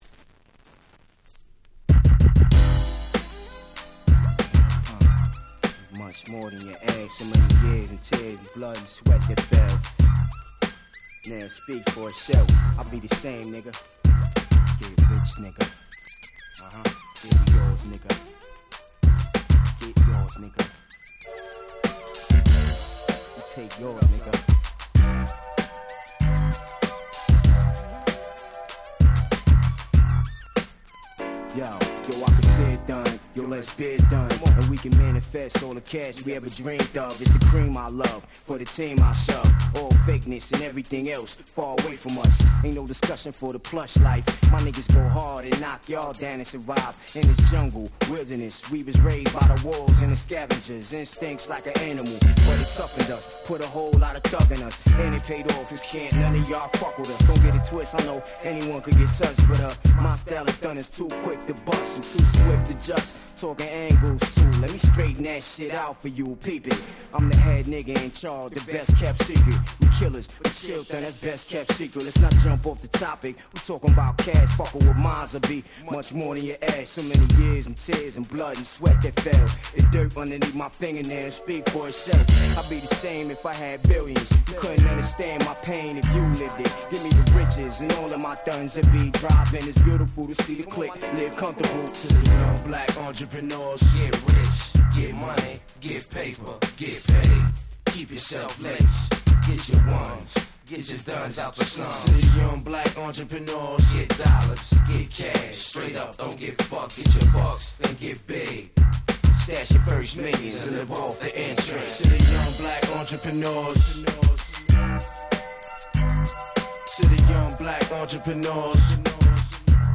00' Smash Hit Hip Hop !!
幻想的なBeatがジワジワきます！！